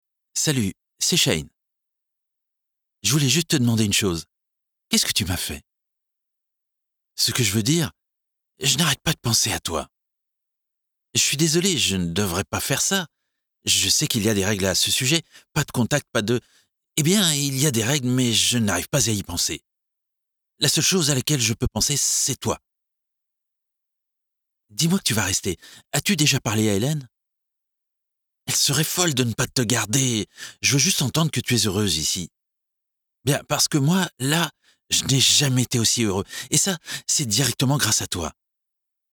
Male
Assured, Character, Confident, Corporate, Engaging, Natural, Reassuring, Smooth, Warm, Versatile, Approachable, Upbeat
French (Native), English (French accent).
Microphone: Neuman M147 U87 // Sennheiser MKH 416 // L22 // Lewitt 640s